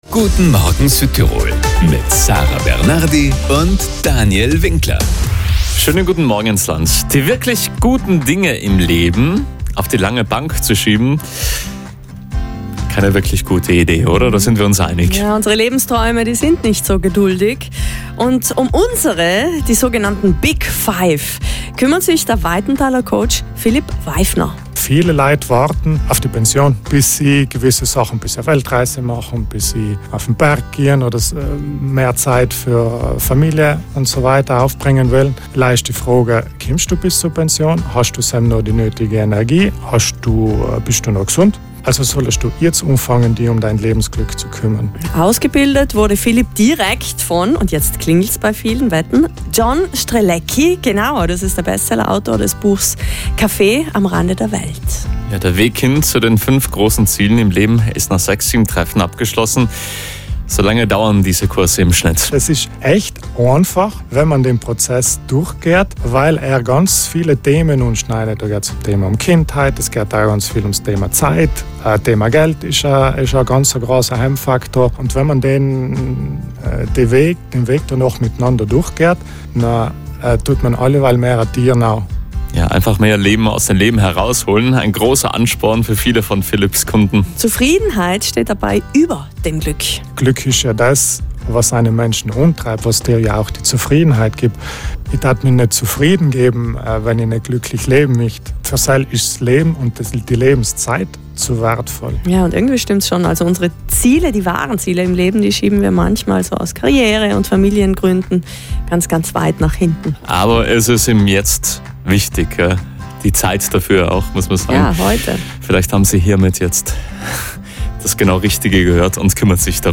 Südtirol 1 — Radio Interview
big-five-interview.mp3